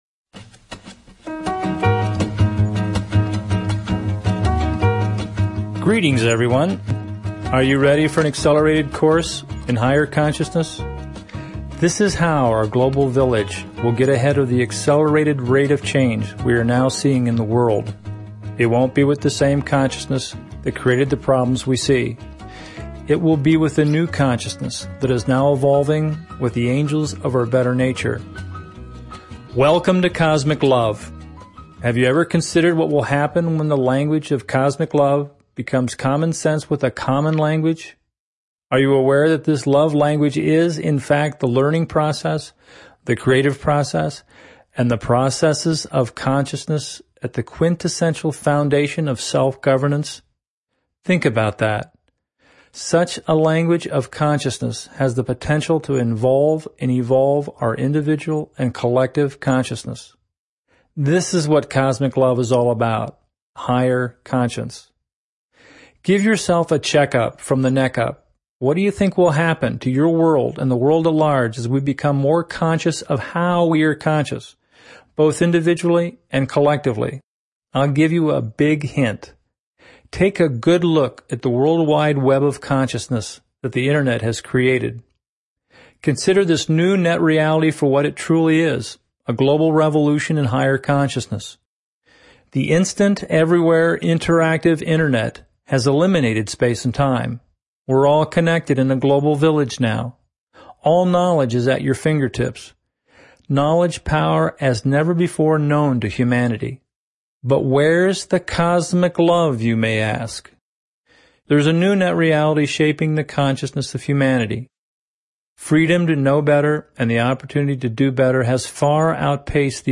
Talk Show Episode, Audio Podcast, Cosmic_LOVE and Courtesy of BBS Radio on , show guests , about , categorized as